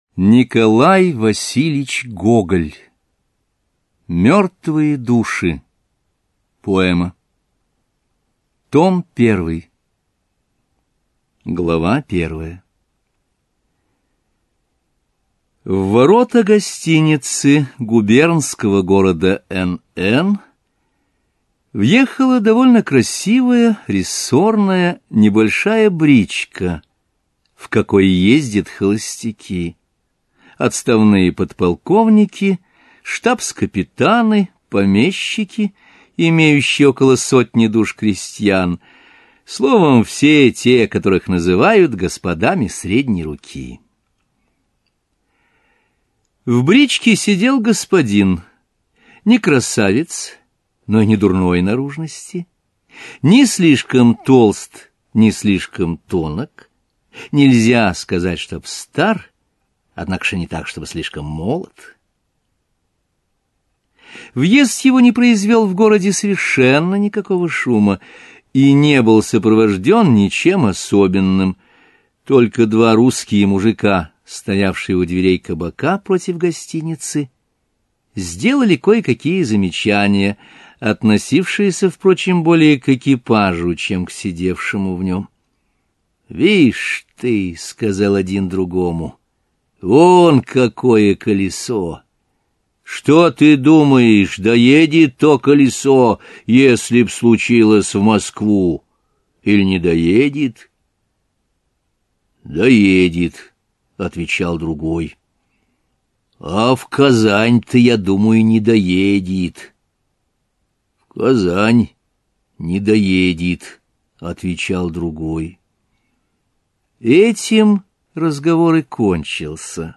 Аудиокнига Мертвые души | Библиотека аудиокниг